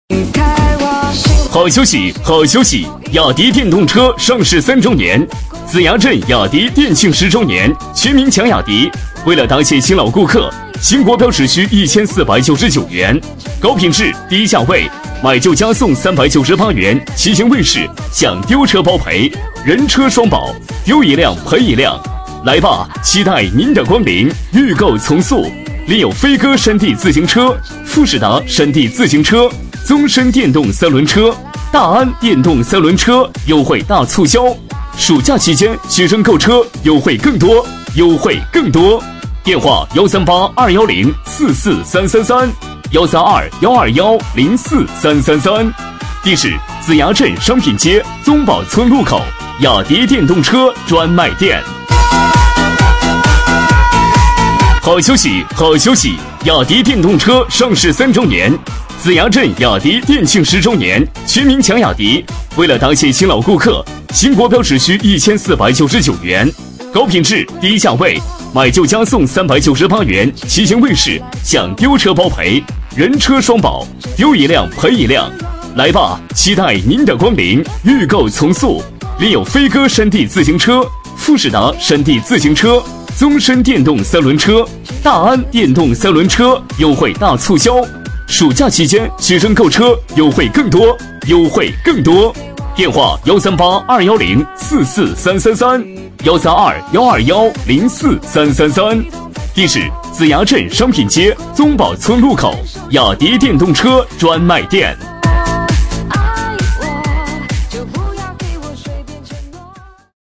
B类男10
【男10号促销】子牙镇雅迪
【男10号促销】子牙镇雅迪.mp3